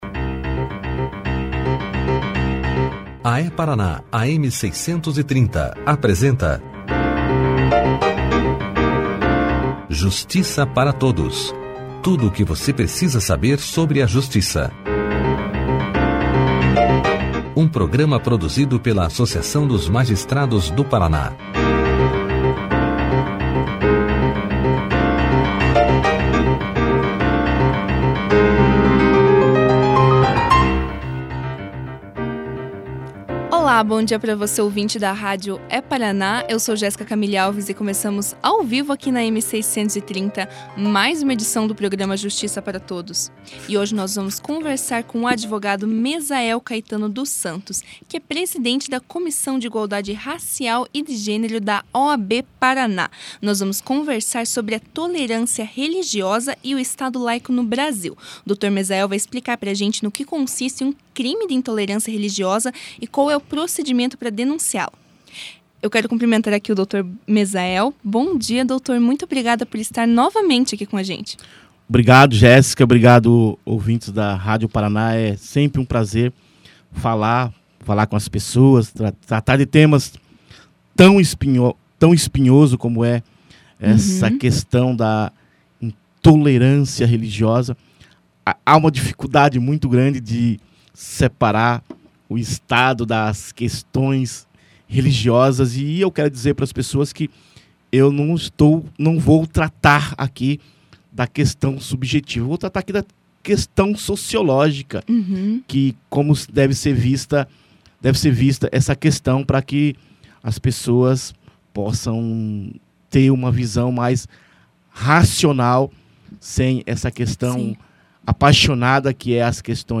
Estão chamando essa prática de crime de ódio que fere a liberdade e a dignidade humana” ressaltou o advogado durante a entrevista.